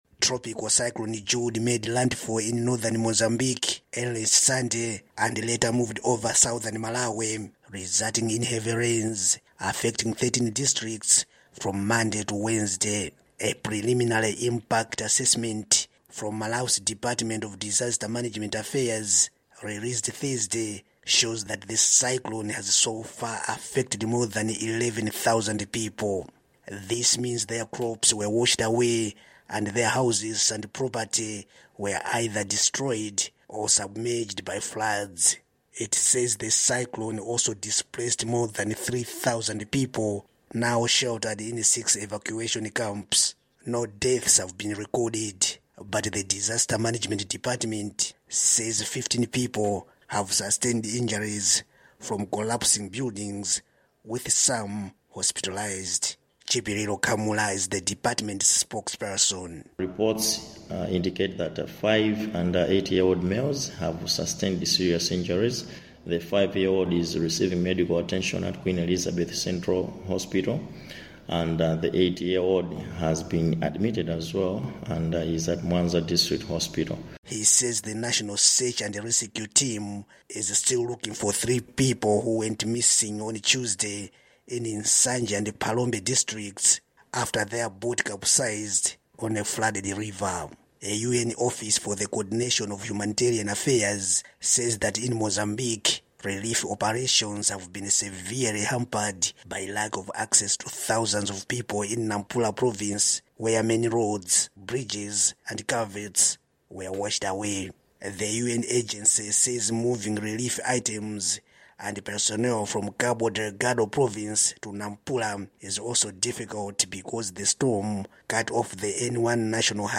Breaking News